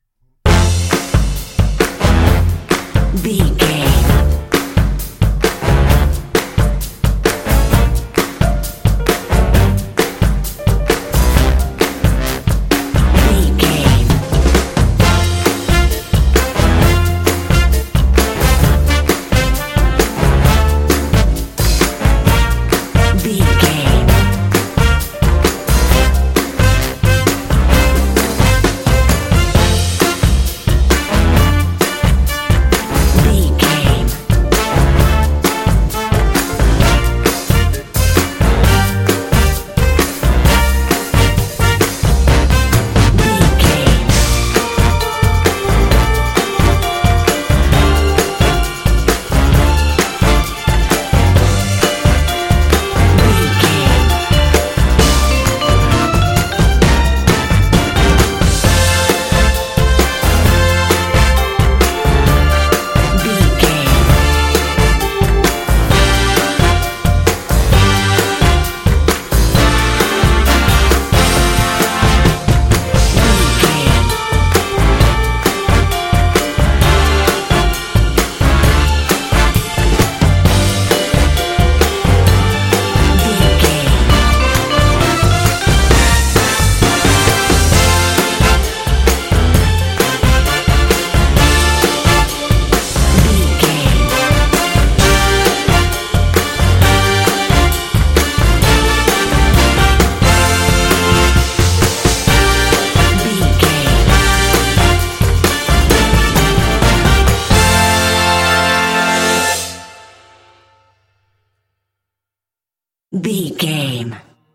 Uplifting
Ionian/Major
happy
bouncy
groovy
drums
brass
electric guitar
bass guitar